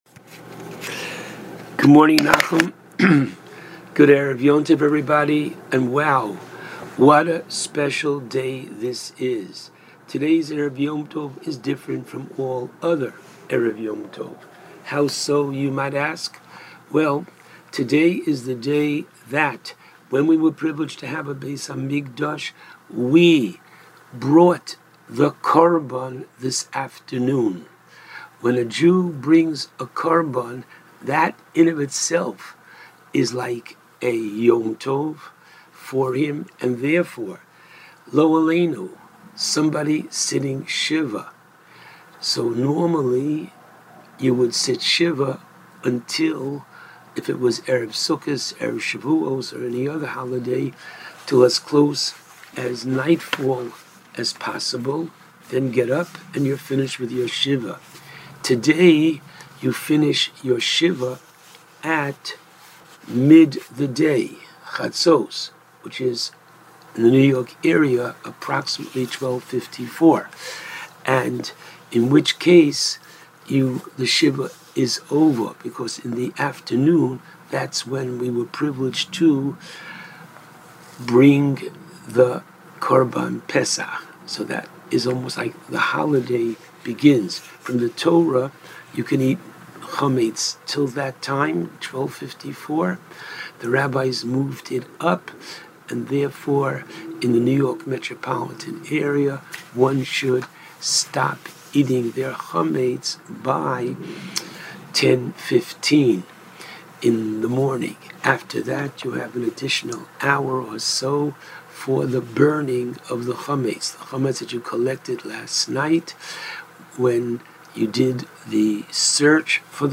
called in to JM in the AM to discuss the various laws and customs of the Pesach Seder.